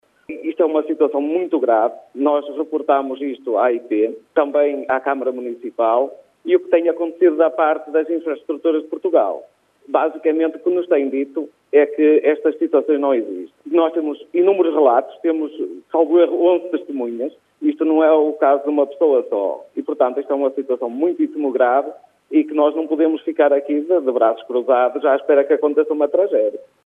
O autarca António Pereira fala em vários testemunhos a comprovar as avarias ao longo deste ano: